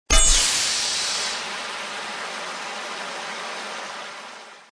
Descarga de Sonidos mp3 Gratis: metal 6.